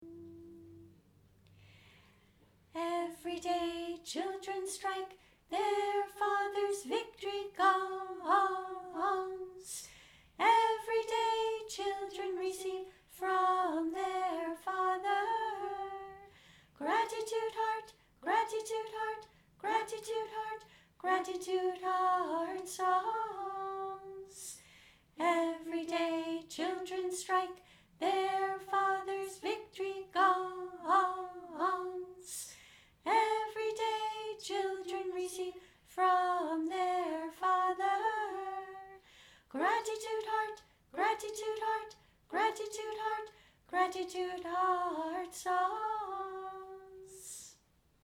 practice recordings